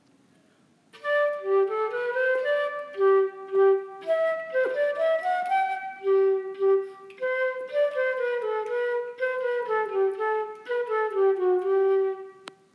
Ouçam o segmento do nosso exímio flautista tocando Bach 7: